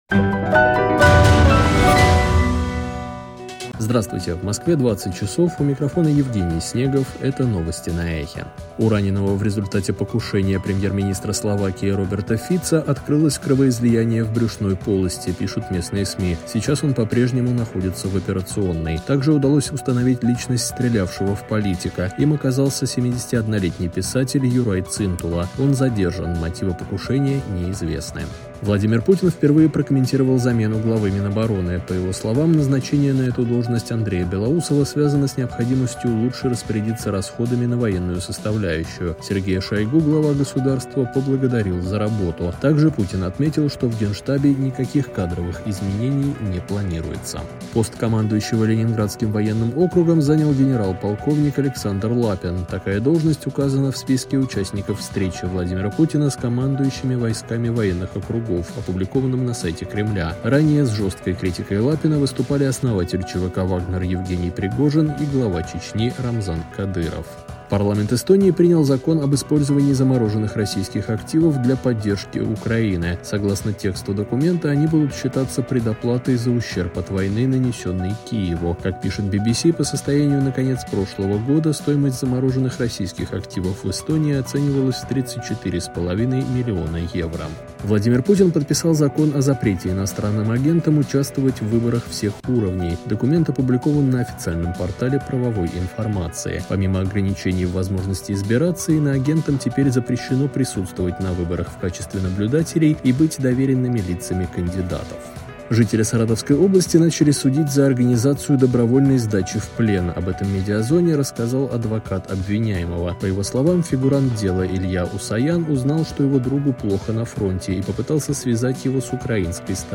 Слушайте свежий выпуск новостей «Эха».